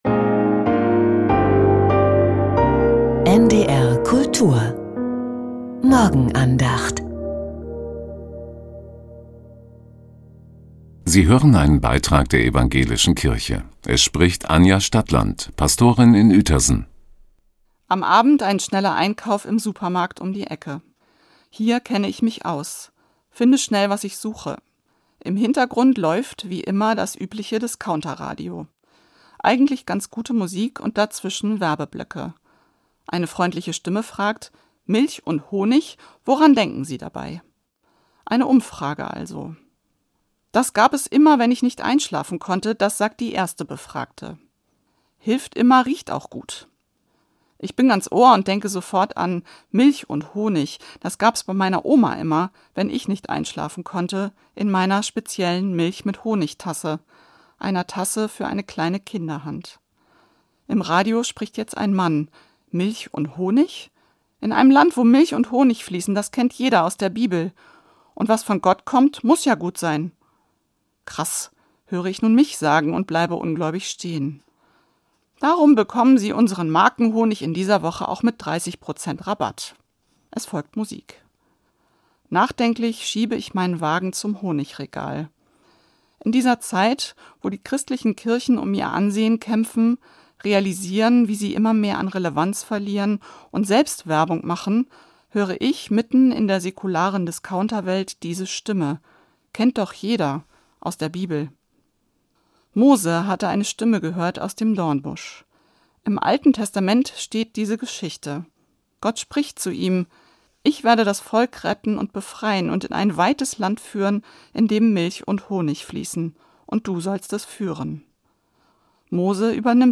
In einem Land, wo Milch und Honig fließen ~ Die Morgenandacht bei NDR Kultur Podcast